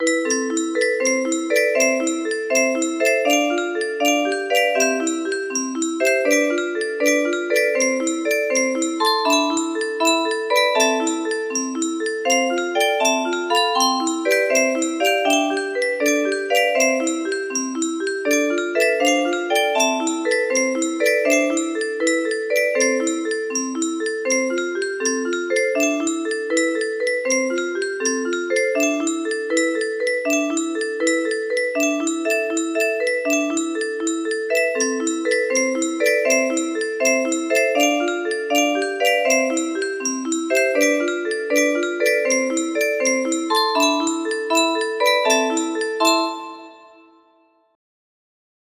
Moldau clone for G30 F scale music box melody
Grand Illusions 30 (F scale)